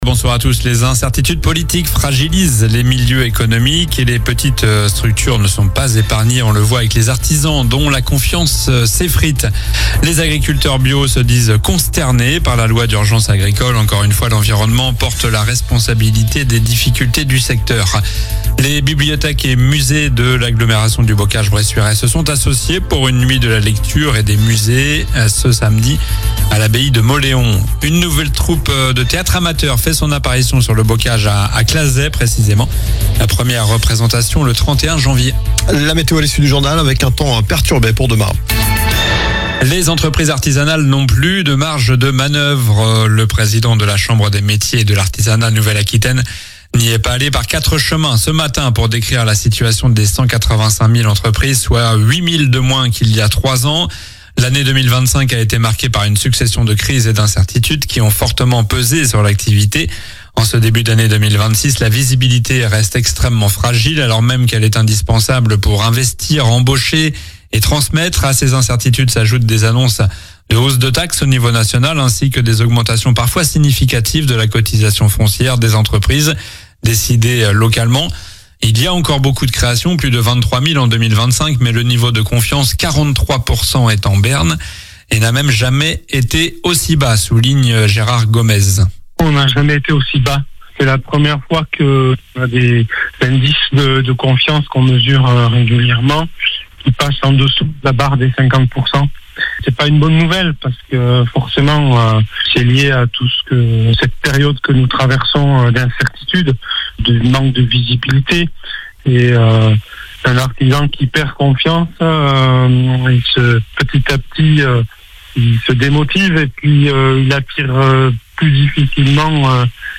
Journal du mardi 20 janvier (soir)